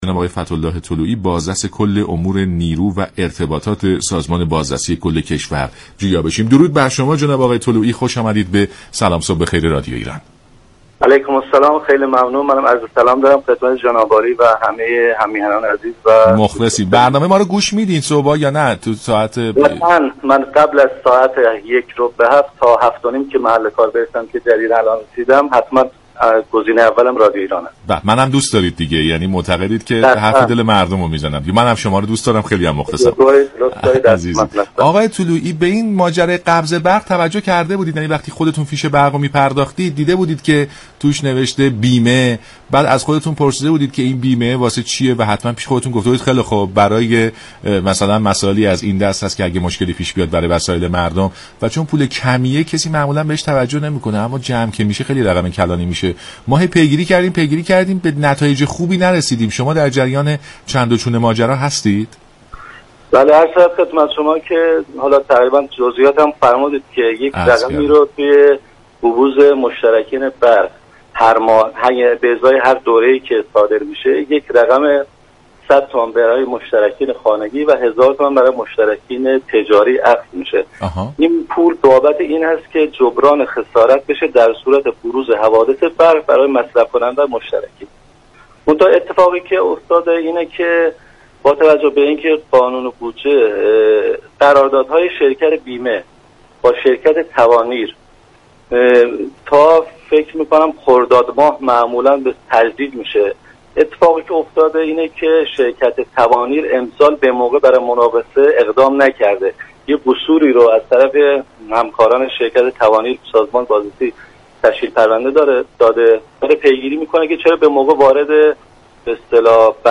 به گزارش شبكه رادیویی ایران، فتح اله طلوعی بازرس كل امور نیرو و ارتباطات سازمان بازرسی كشور در برنامه سلام صبح بخیر به مبلغ بیمه قبض های برق پرداخت و گفت: مبلغی كه مشتركان برق به عنوان بیمه پرداخت می كنند برای جبران خساراتی است كه به هنگام بروز حادثه پدید می آید.